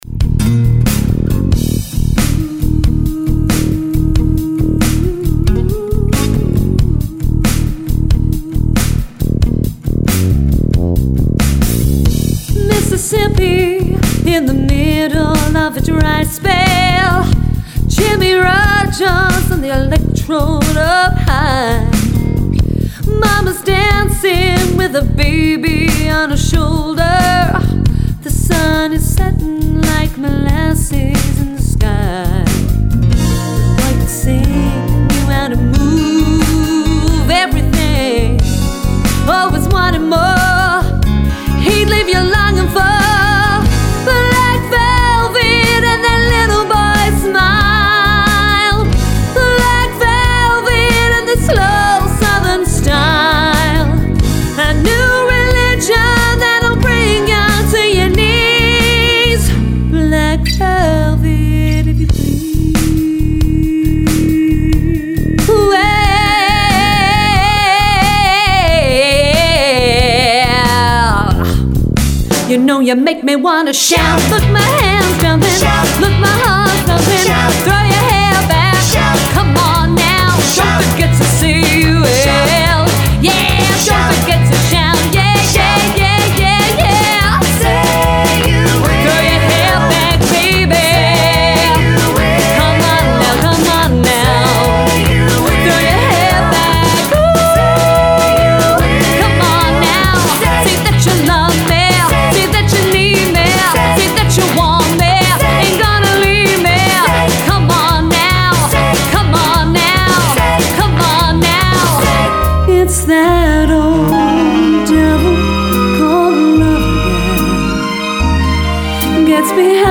She has a big voice and a big personality!